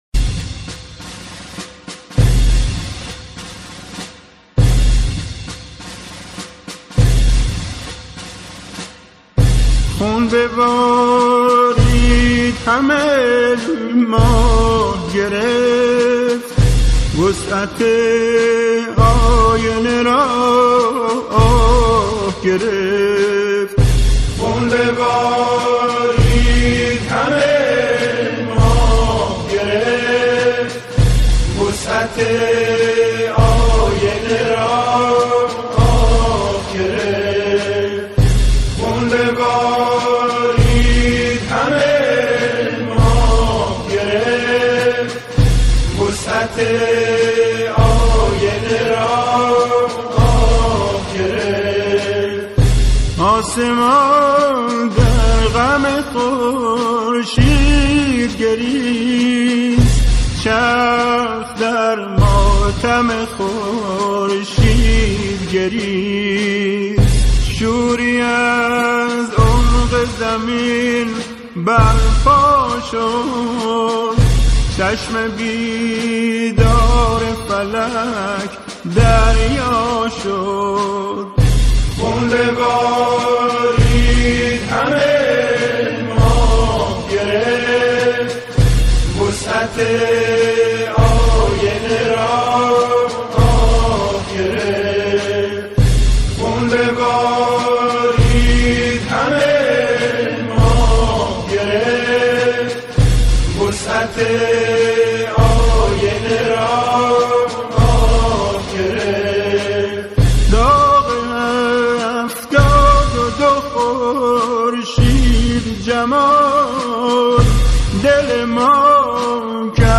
با اجرای گروهی از جمعخوانان